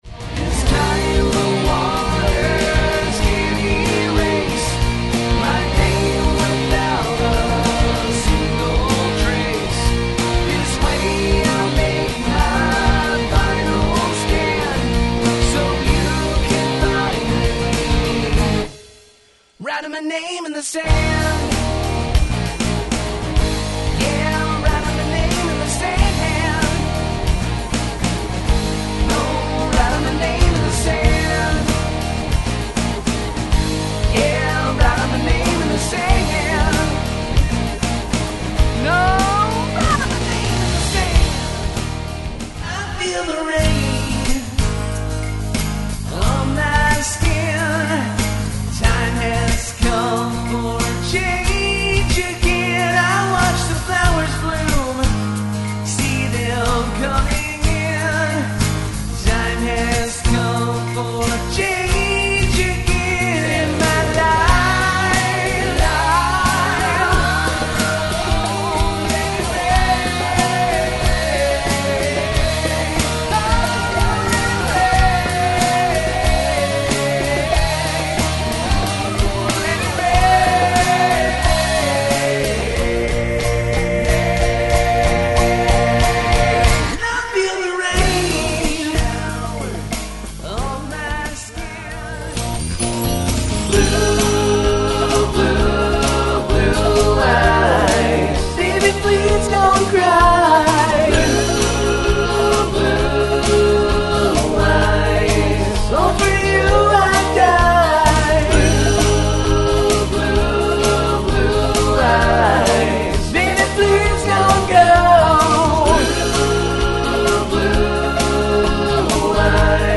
Electric & Bass Guitars.